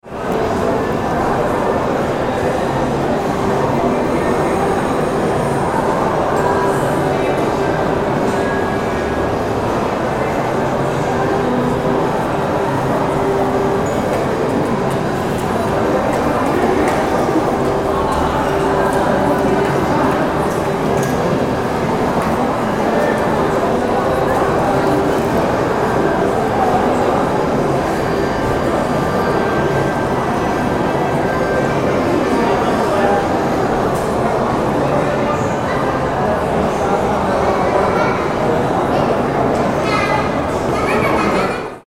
Shopping-mall-play-area-ambience-sound-effect.mp3